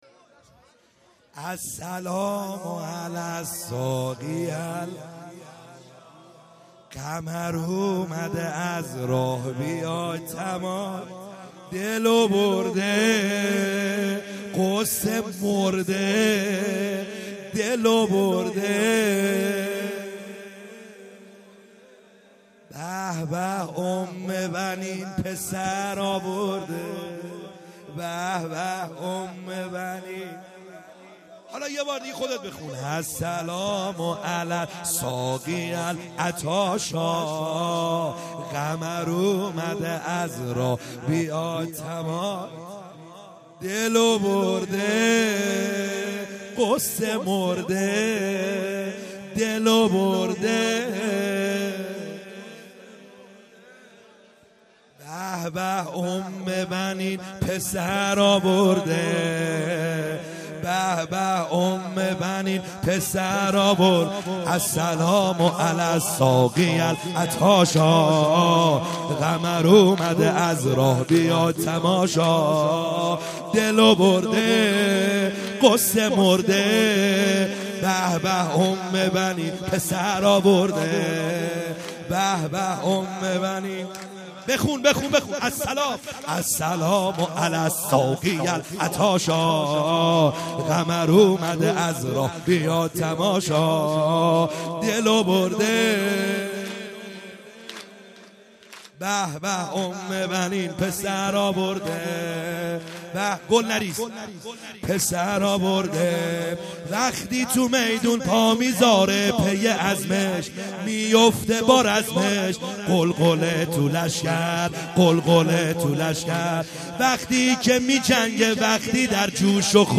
خیمه گاه - بیرق معظم محبین حضرت صاحب الزمان(عج) - سرود | السلام علی الساقی العطاشا